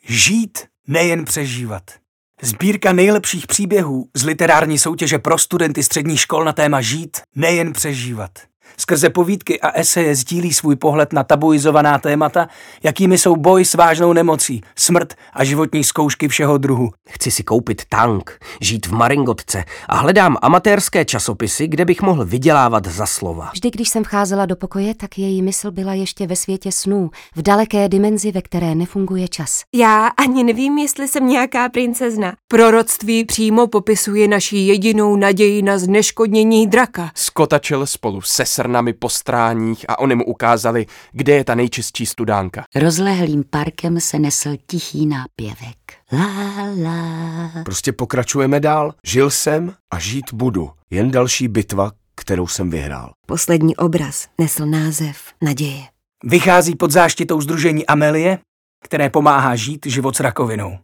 Žít, nejen přežívat audiokniha
Ukázka z knihy
Natočeno v roce 2024 ve studiu NAPA Records s.r.o.